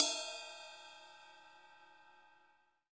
CYM RIDE40BR.wav